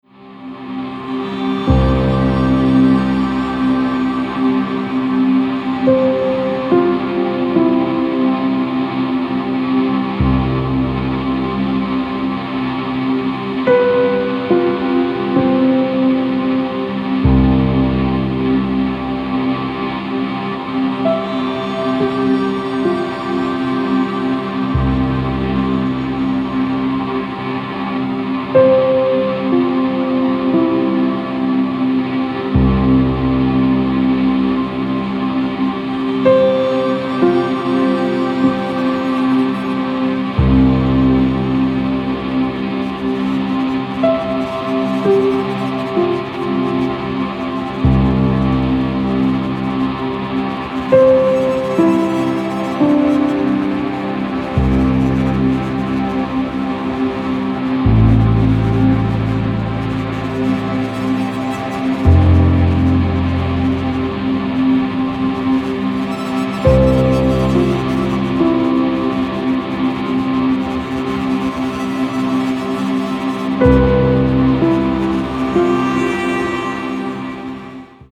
AMBIENT/DOWNTEMPO